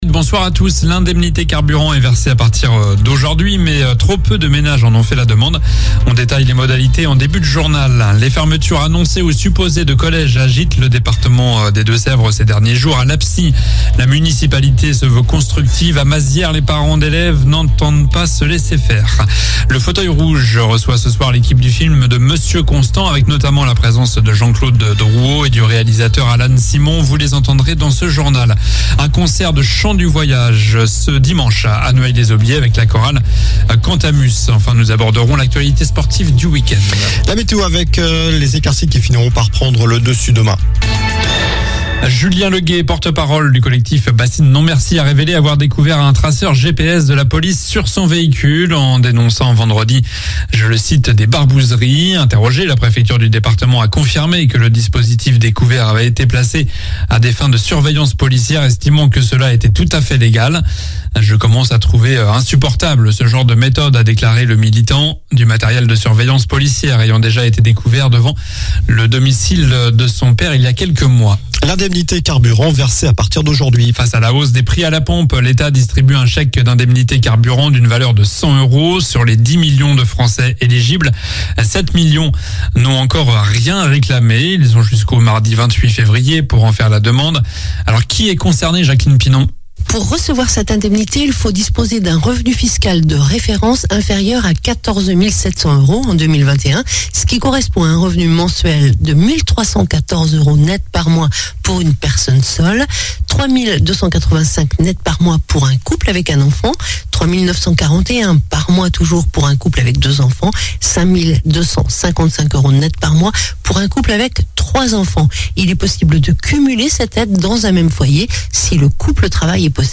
Journal du vendredi 27 janvier (soir)